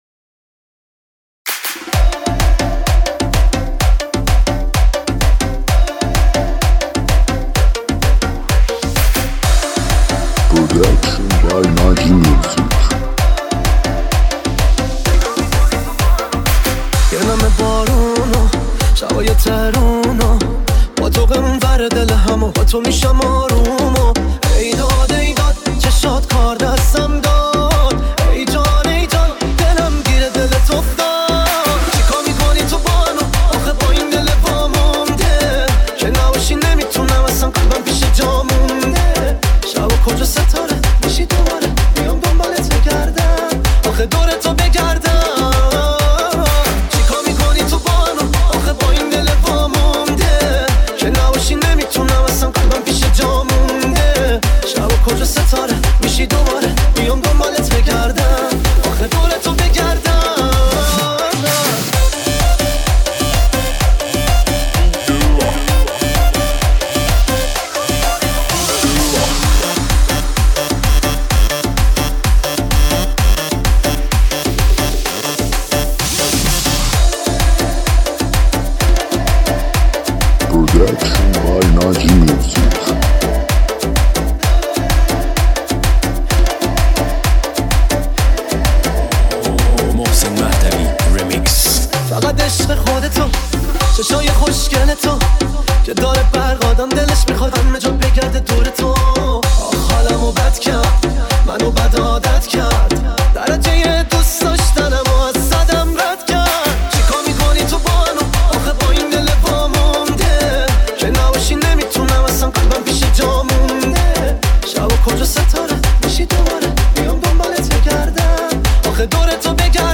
ریمیکس شاد تریبال جشنی
ریمیکس شاد تریبال مخصوص رقص